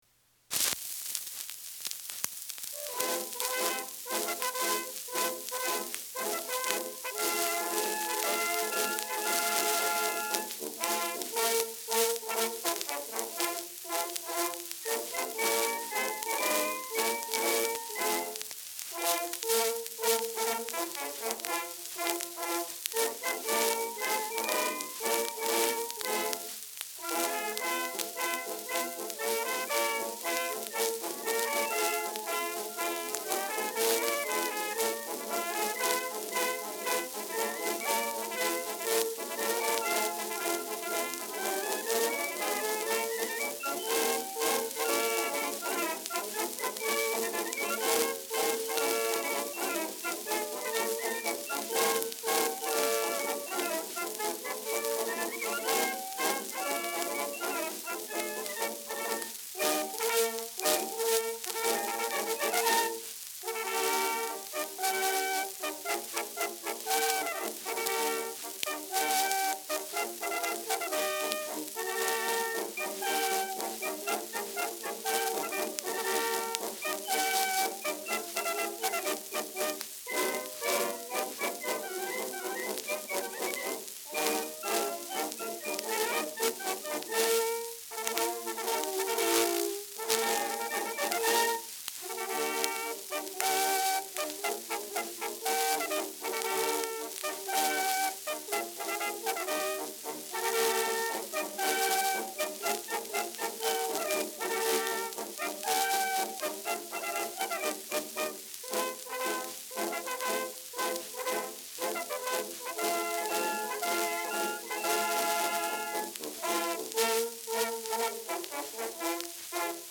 Schellackplatte
Tonarm springt stark